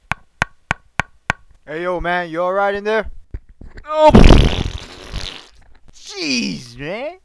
knocktoilet.wav